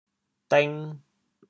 潮州 dêng2 白 对应普通话: děng ①古代指顿齐竹简（书）。
dêng2.mp3